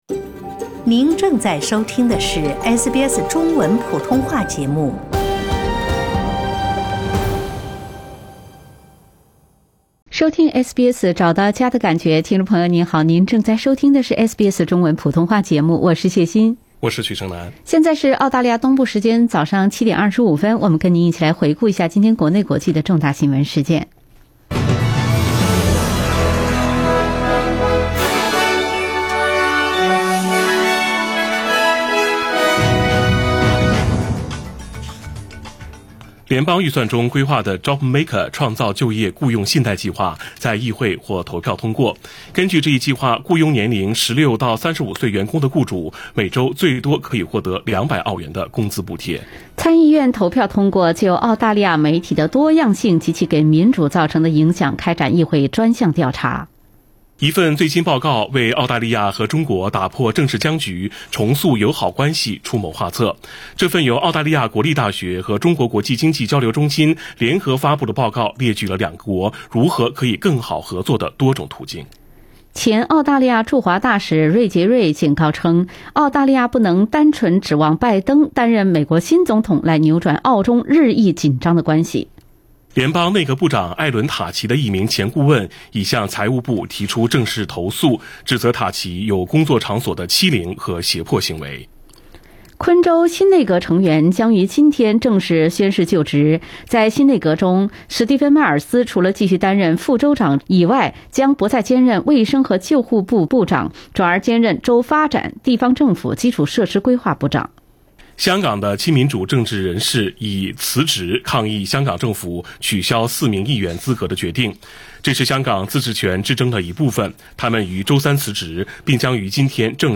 SBS早新闻（11月12日）